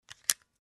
Звуки пейджера
Звук извлечения батарейки из пейджера